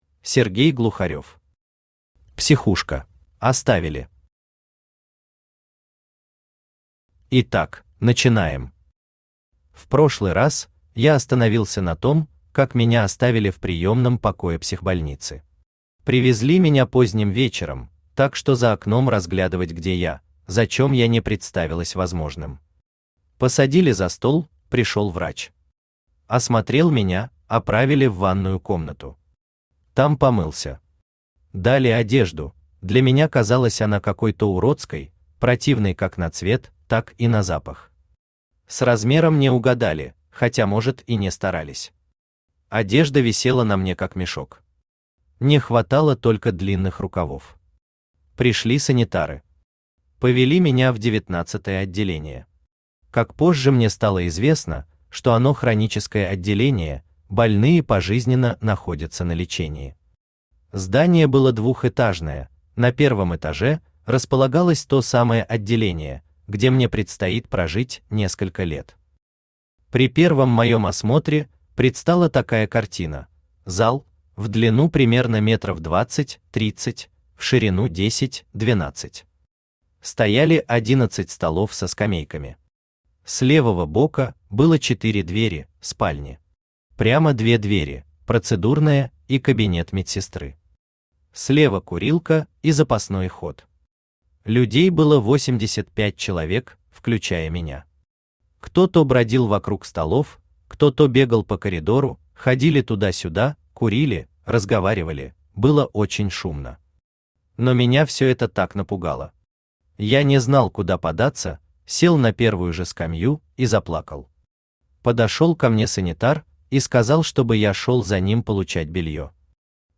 Аудиокнига Психушка | Библиотека аудиокниг
Aудиокнига Психушка Автор Сергей Викторович Глухарёв Читает аудиокнигу Авточтец ЛитРес.